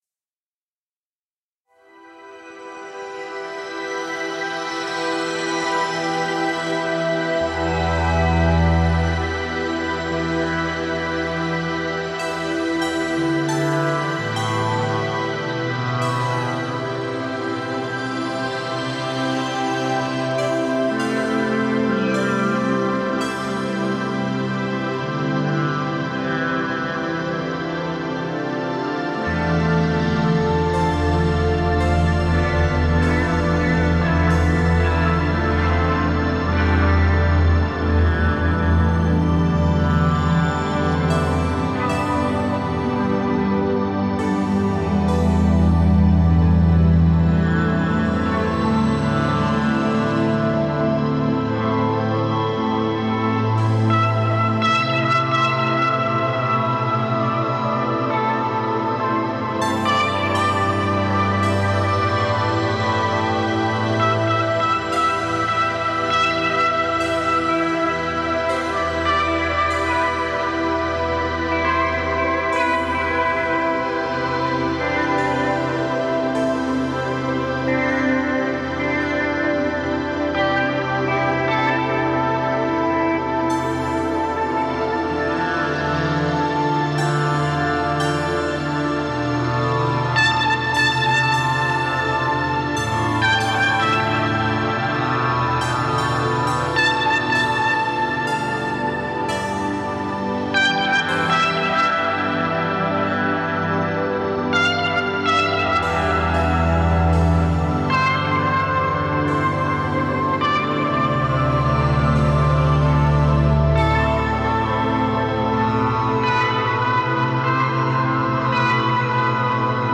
本册重点介绍地狱的黑暗和更险恶的声音，以及炼狱的快感。可以肯定的是，这70个预设非常适合旋律工作，可以产生音景，邪恶和真正的令人不快的声音。但这一切都非常响亮！